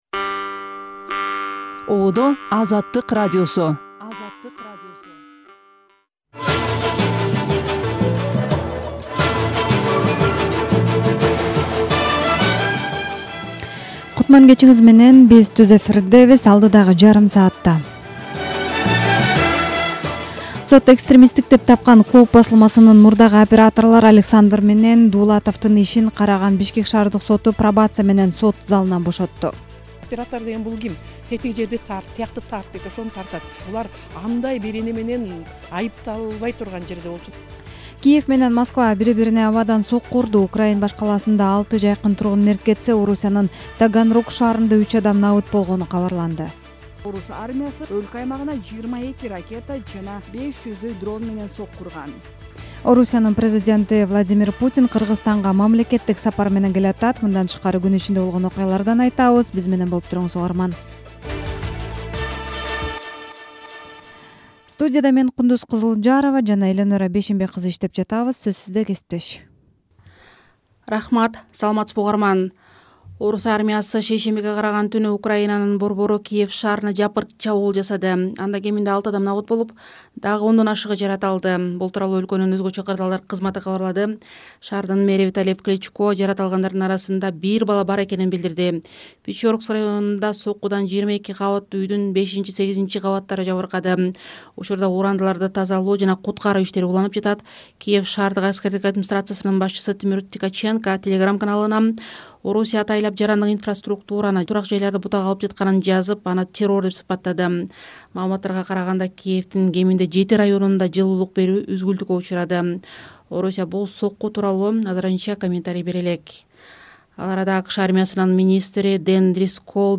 Жаңылыктар | 25.11.2025 | Лейлекте Тажикстан менен алмашылган үйлөрдүн тургундары көчүрүлүүдө